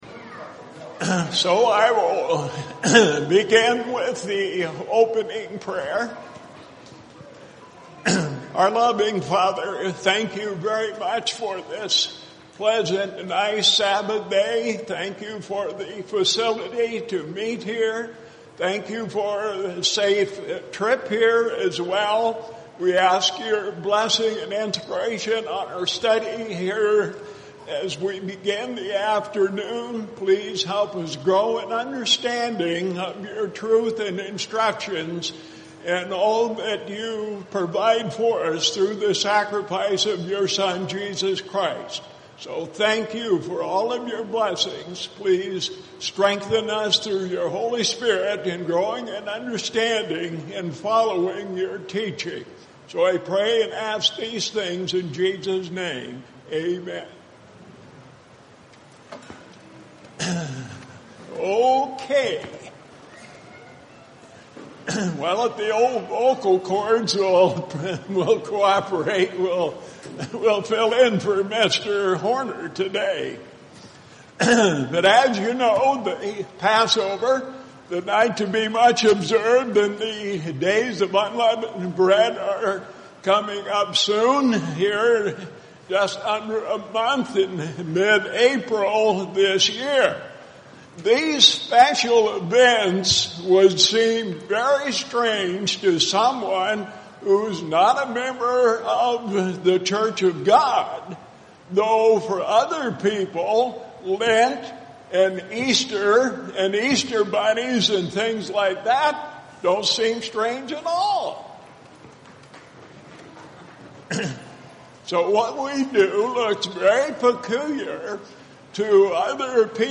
Listen to this informative Bible Study.
UCG Sermon Studying the bible?